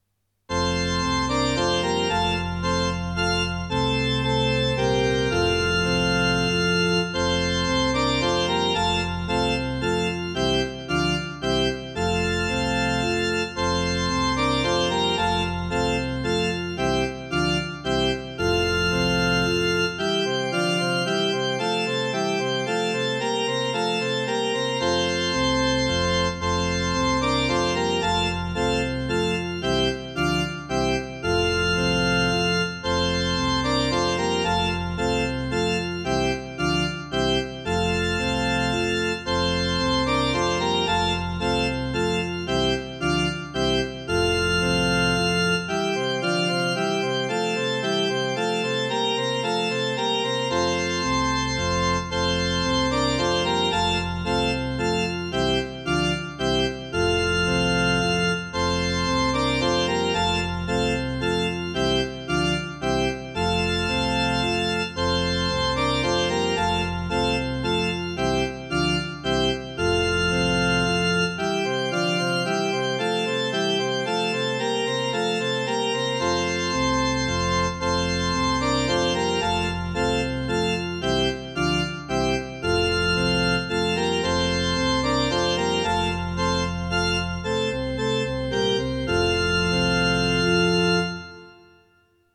こども讃美歌22番
Child-Hymn_No.22.mp3